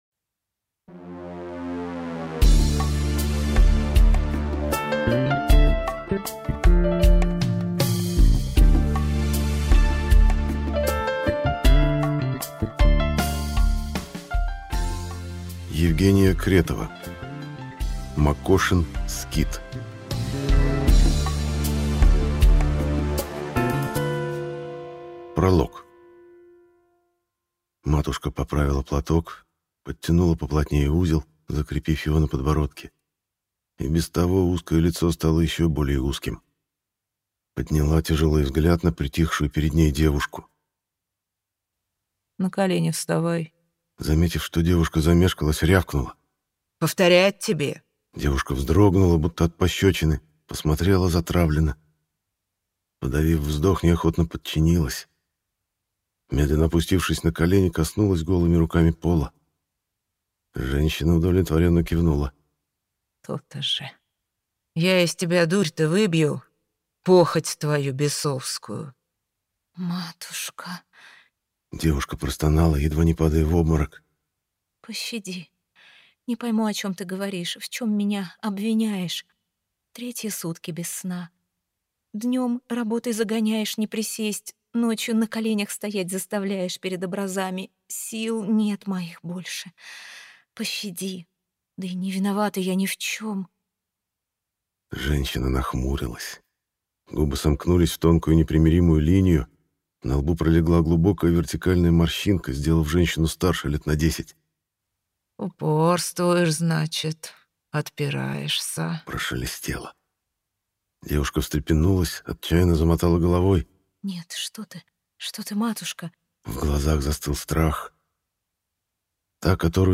Аудиокнига Макошин скит | Библиотека аудиокниг